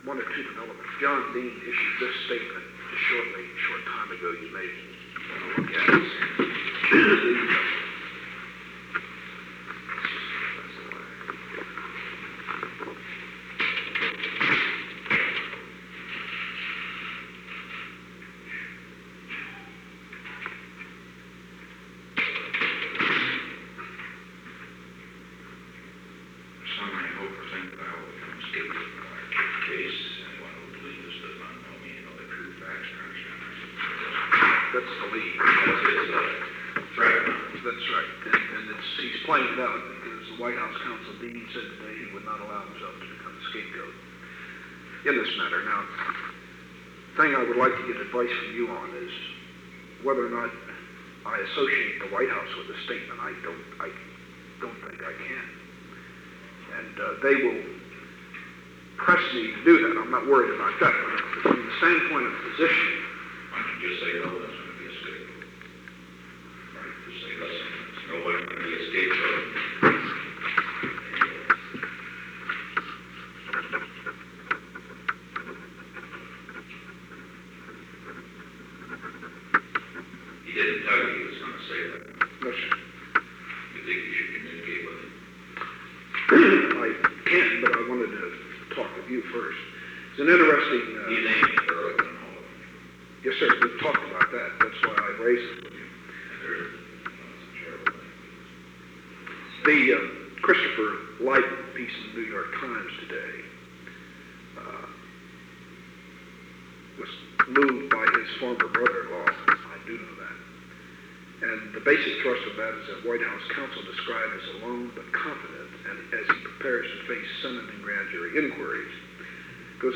Conversation No. 902-5 Date: April 19, 1973 Time: Unknown between 12:29 pm and 12:48 pm Location: Oval Office The President and Ronald L. Ziegler entered. This recording began at an unknown time while the conversation was in progress.
Secret White House Tapes